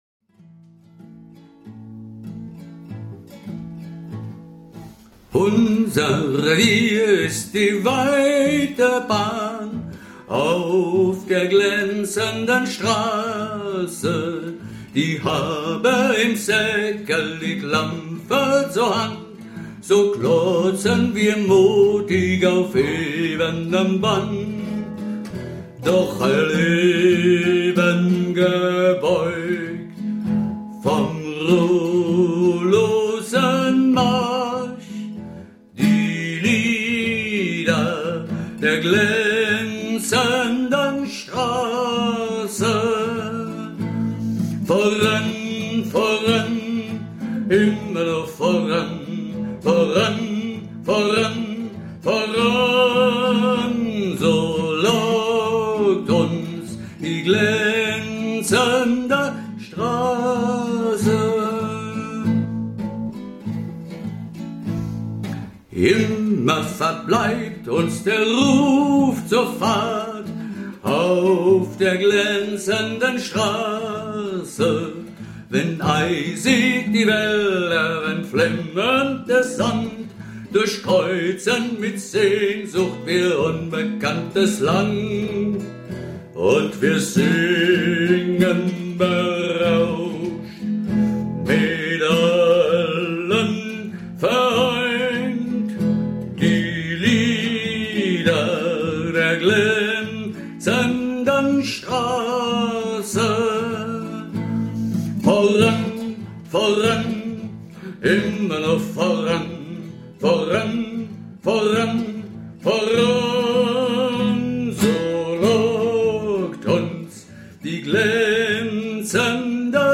Werkstatt - Aufnahmen 21